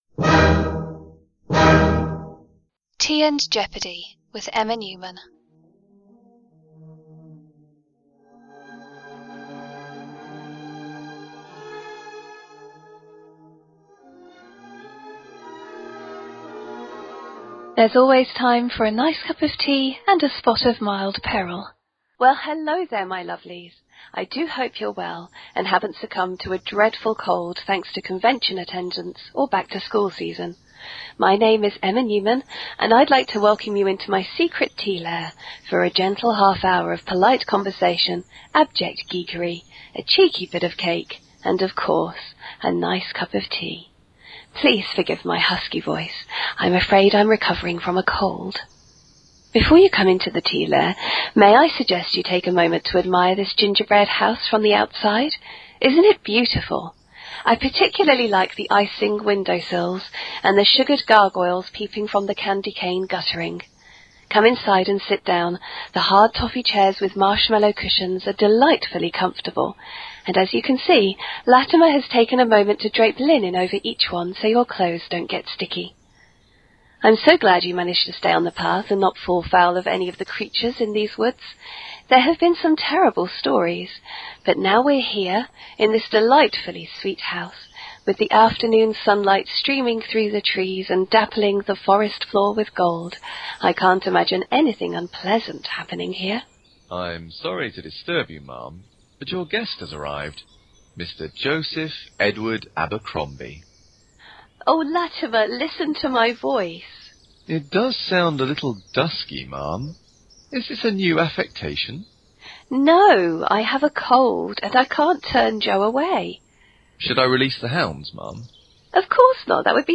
Tea and Jeopardy 11 – A Chat With Joe Abercrombie